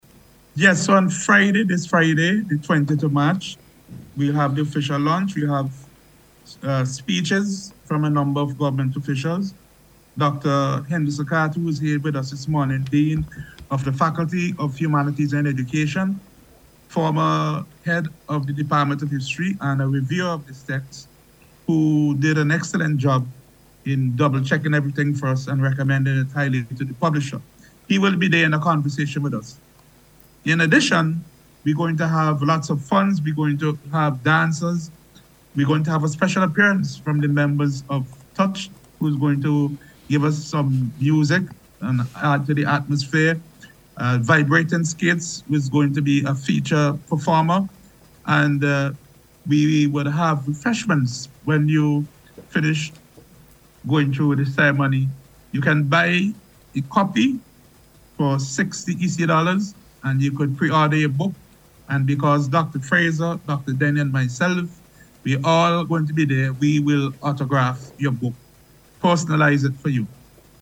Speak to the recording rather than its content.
Speaking on NBC’s Talk Yuh Talk programme this week